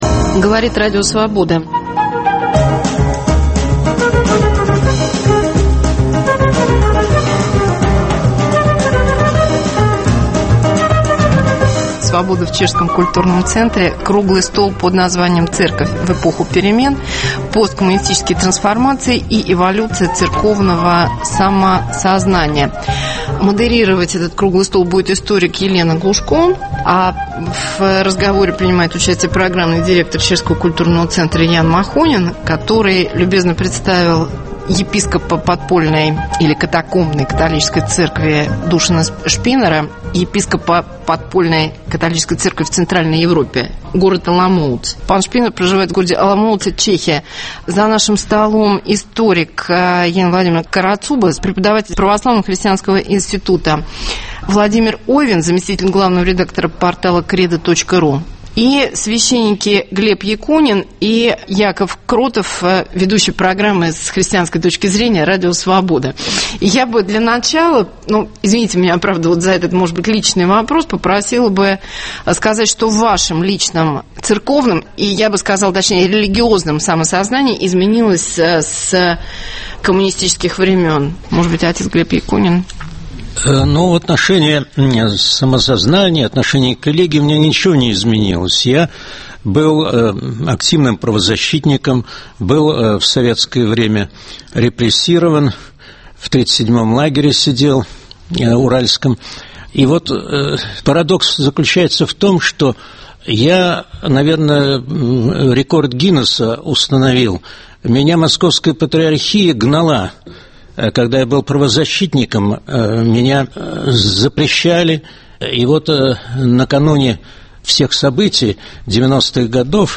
Свобода в Чешском культурном центре. Церковь в эпоху перемен. Посткоммунистические трансформации и эволюция церковного самосознания.